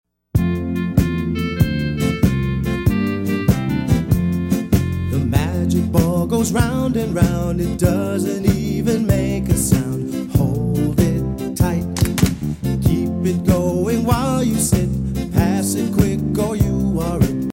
Exercise Song Lyrics and Sound Clip
Action Song Lyrics